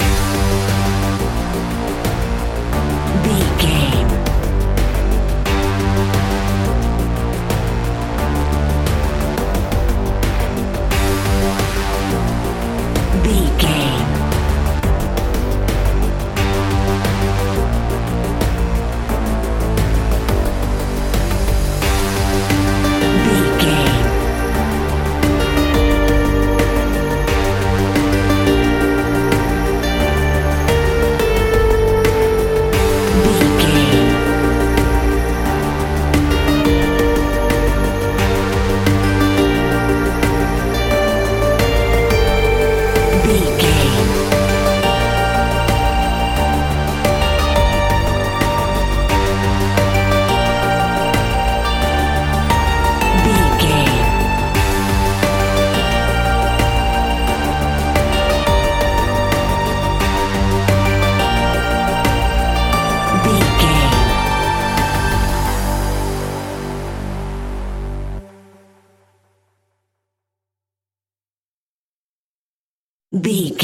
In-crescendo
Thriller
Aeolian/Minor
scary
tension
ominous
dark
haunting
eerie
synthesizer
drum machine
electronic music
electronic instrumentals
Horror Synths